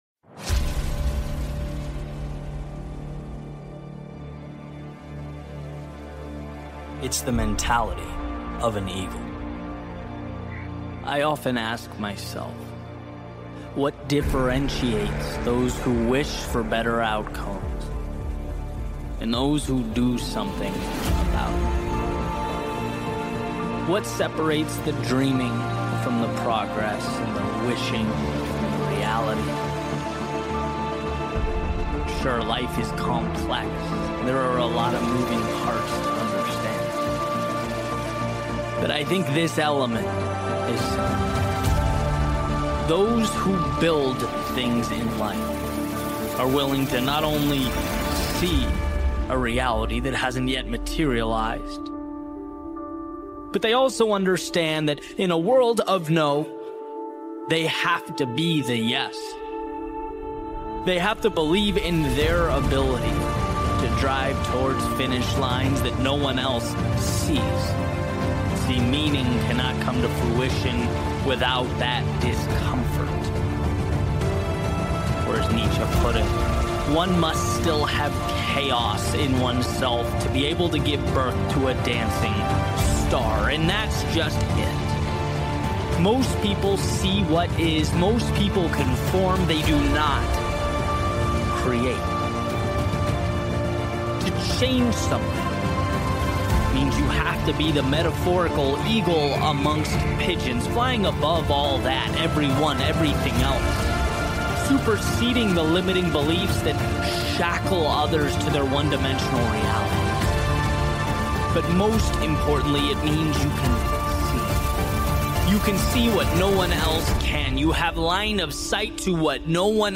Building a Life Beyond Limits - Powerful Motivational Speech